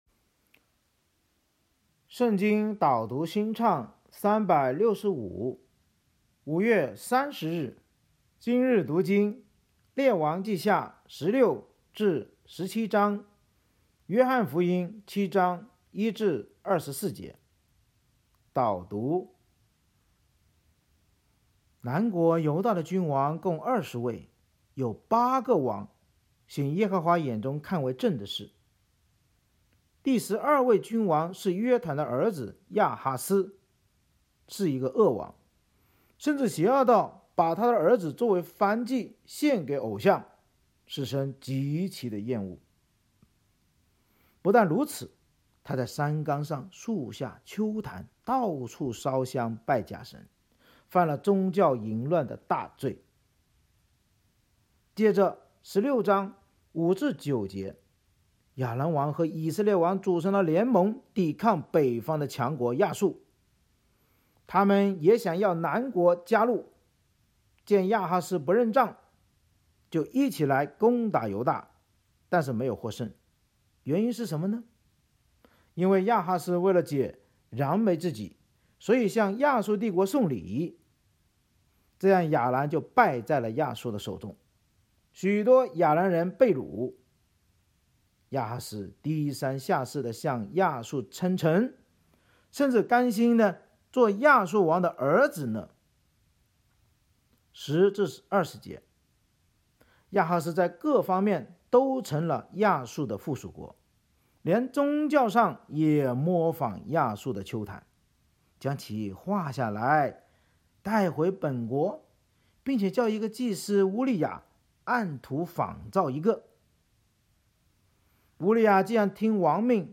圣经导读&经文朗读 – 05月30日（音频+文字+新歌）